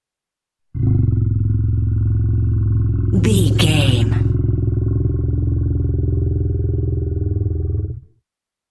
Monster growl epic creature
Sound Effects
scary
ominous
dark
eerie
angry